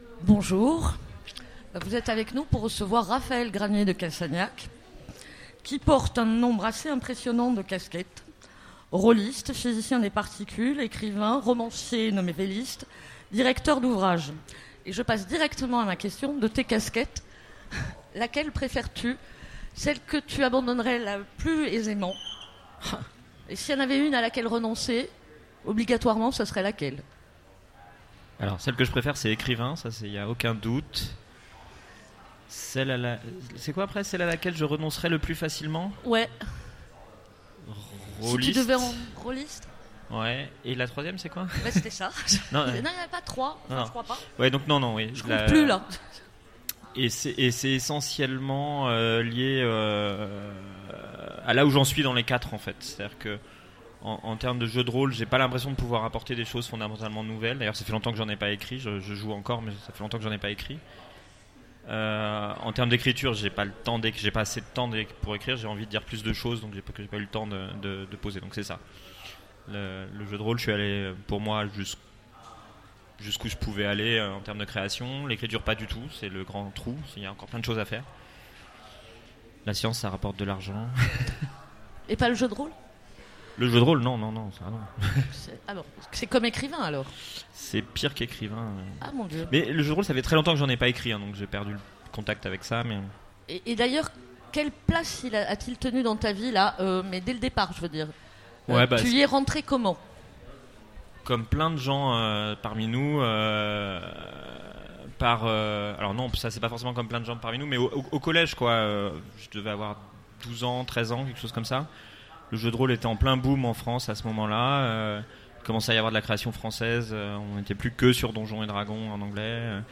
Rencontre avec un auteur Conférence